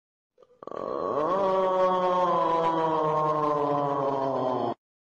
Plankton Aughhhh Meme